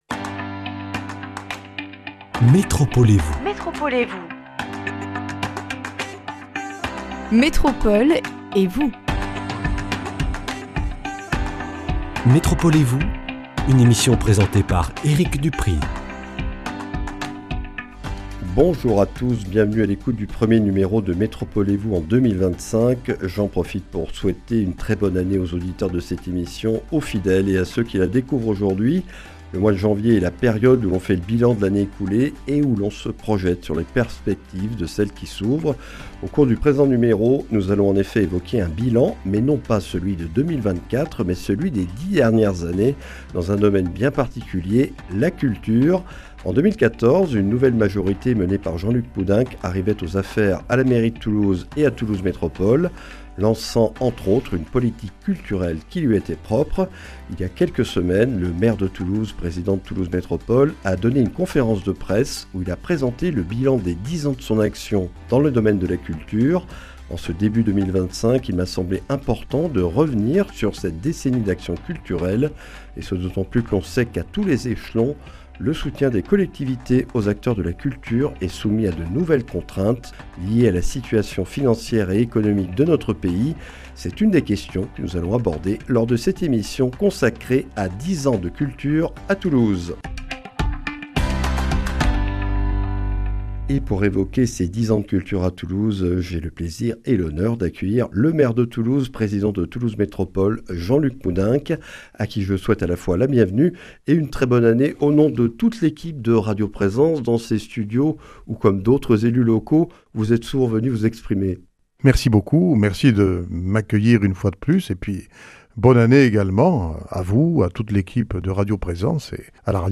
Jean-Luc Moudenc, maire de Toulouse et président de Toulouse Métropole, est notre invité pour évoquer le bilan de son action dans le domaine de la culture depuis 2014. Nous consacrons le 1er volet de nos échanges à rappeler les responsabilités et missions de la mairie de Toulouse et de Toulouse Métropole dans le secteur culturel, les événements et équipements qu’elles gèrent ou financent.